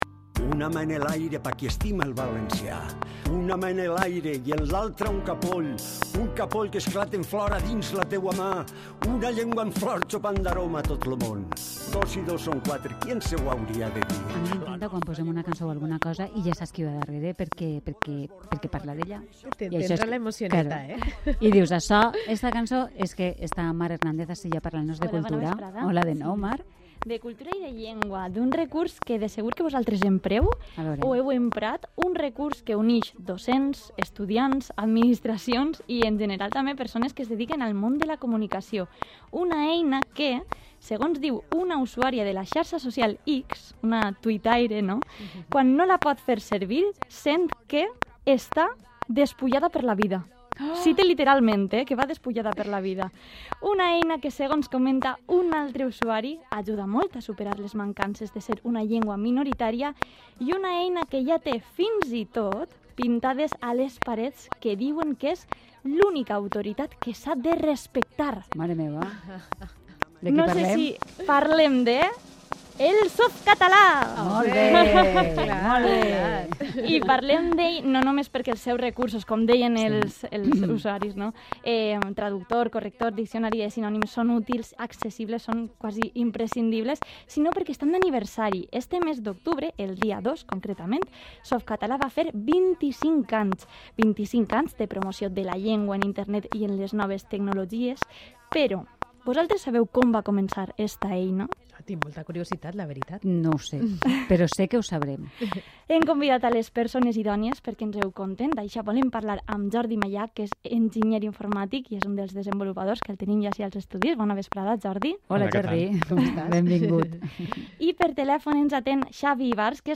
Entrevista a Softcatalà: